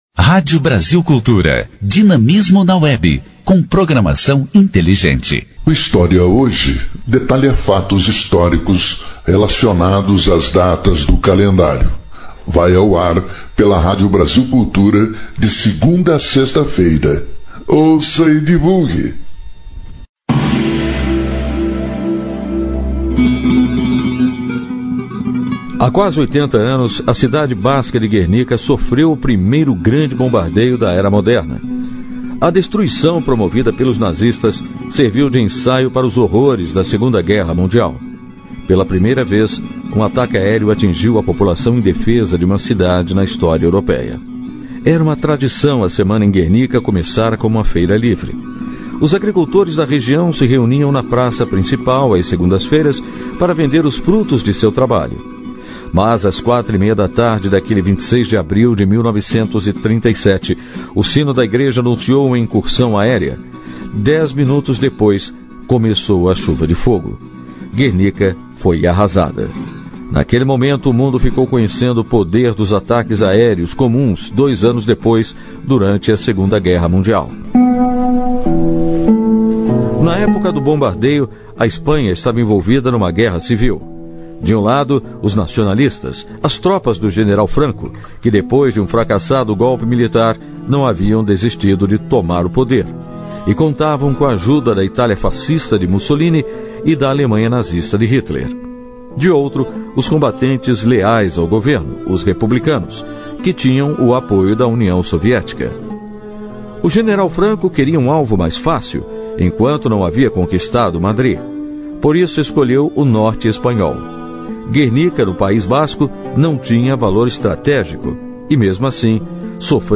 História Hoje : Programete sobre fatos históricos relacionados às datas do calendário. Vai ao ar pela Rádio Brasil Cultura segunda a sexta-feira.